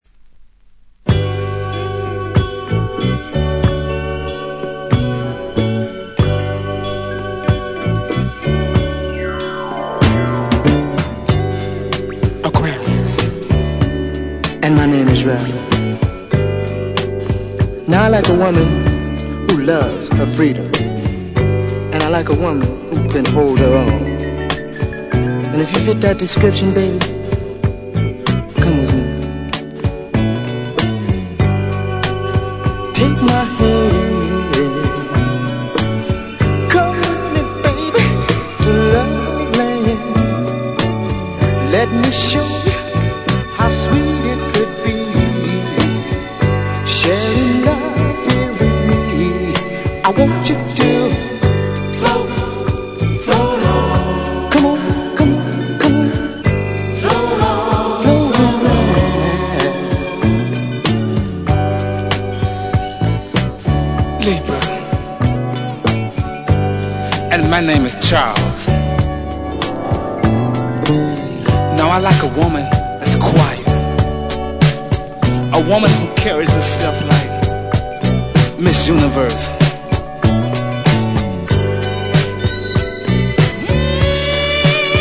Genre: SOUL ORIG / REISS